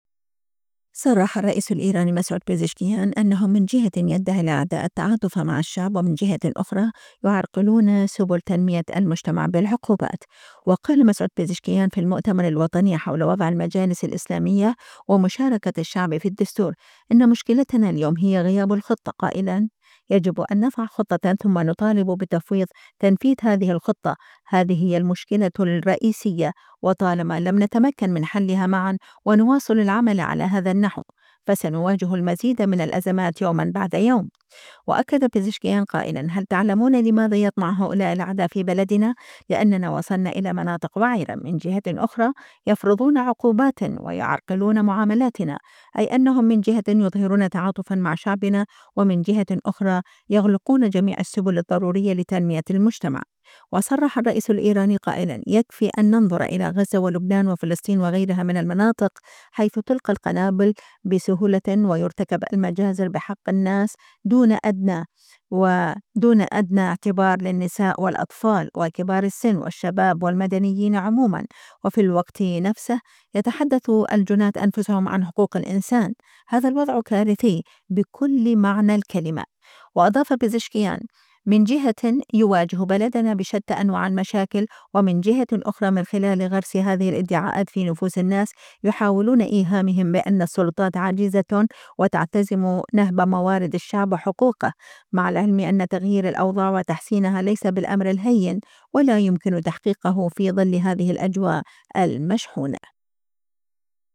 وقال مسعود بزشكيان في المؤتمر الوطني حول وضع المجالس الإسلامية ومشاركة الشعب في الدستور: أن مشكلتنا اليوم هي غياب الخطة، قائلاً: يجب أن نضع خطة، ثم نطالب بتفويض تنفيذ هذه الخطة.